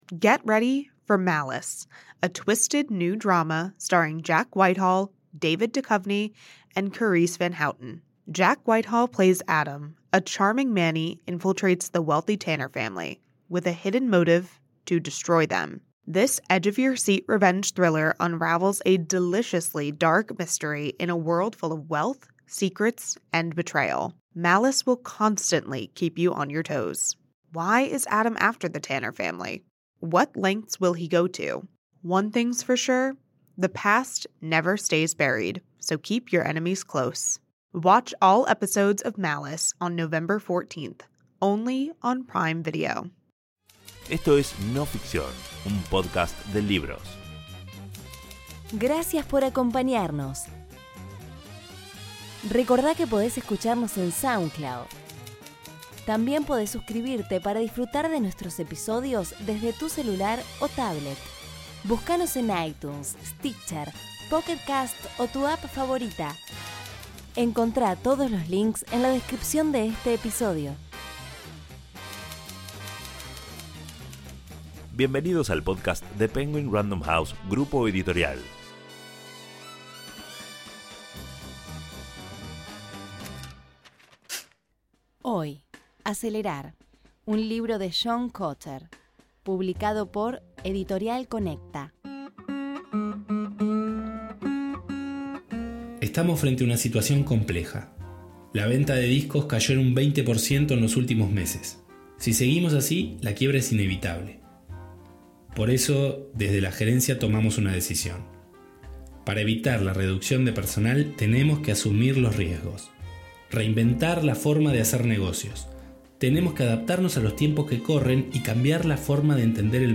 Actuación
Narradora